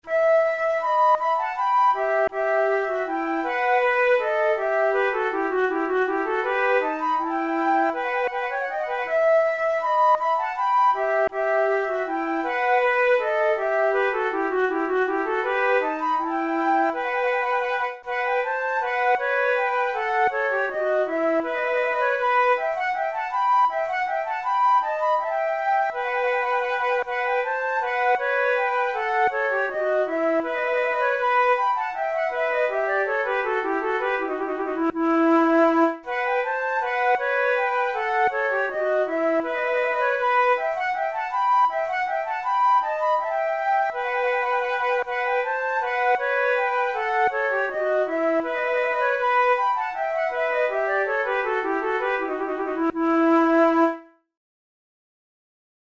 Flute duet by J.B. de Boismortier
Categories: Baroque Minuets Difficulty: easy